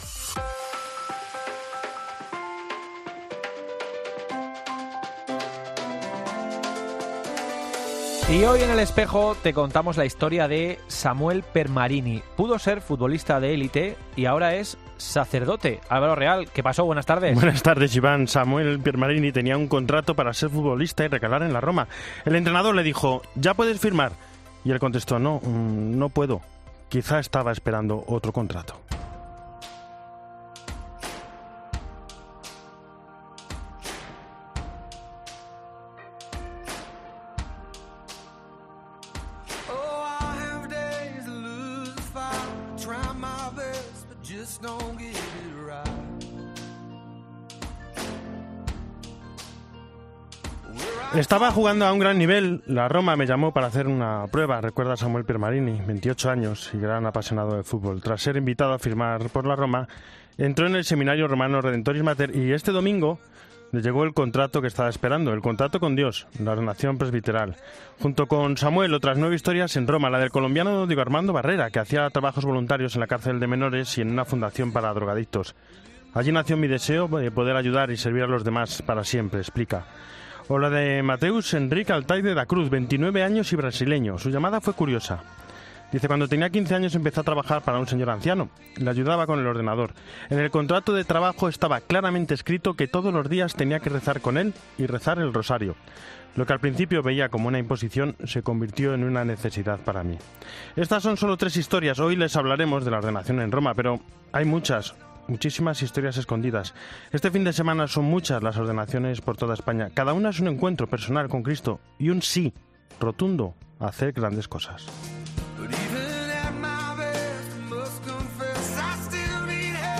entrevista vocaciones nativas e Hispanoamérica.